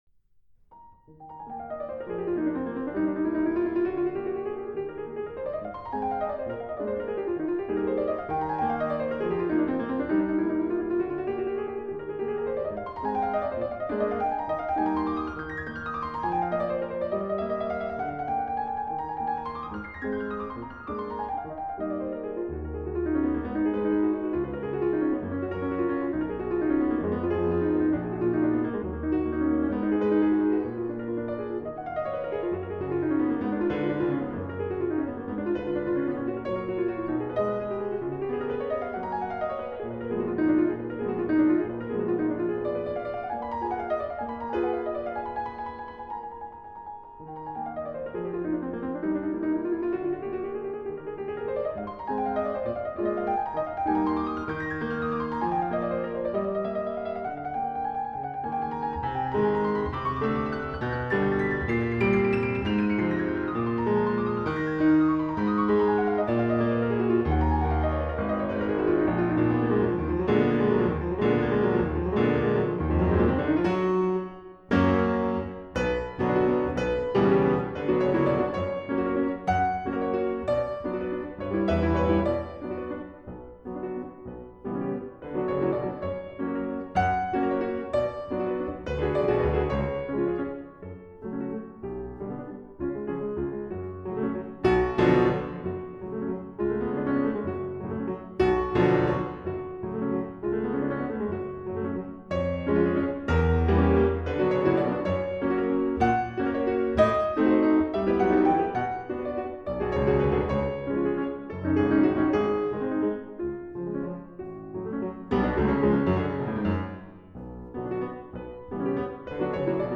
中でも４つの即興曲の２番目にあたるこの曲は、清い水の流れのような音階の上下とリズミカルで逞しい和音が繰り替えされています。
右手の旋律と、静かに宥めるような左の伴奏のバランスが、今思いついたかのようなアンプロンプチュ（即興曲）性を高めています。
繊細で煌びやかで美しい旋律、音の遷移を遊ぶように気持ちよくを奏でることができます。